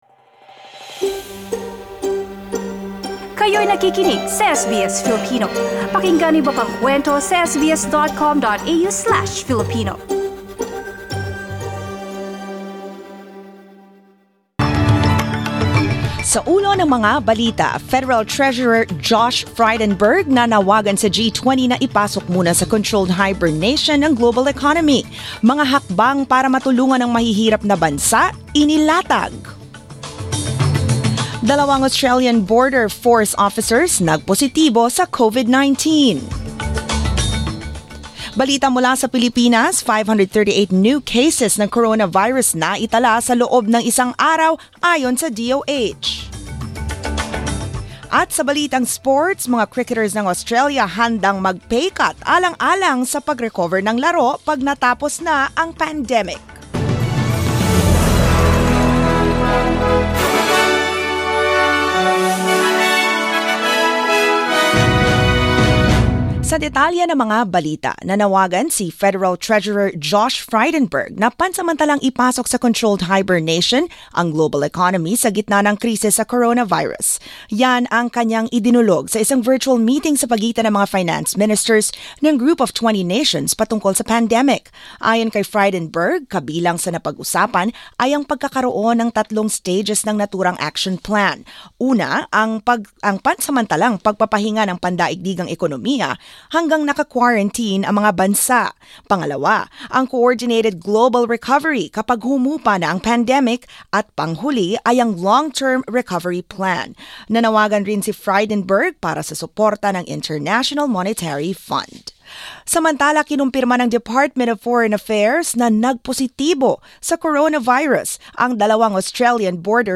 SBS News in Filipino, Wednesday 1 April